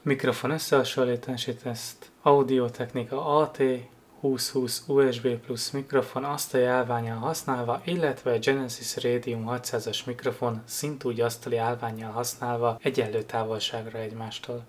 Hangminőség teszt: összehasonlítás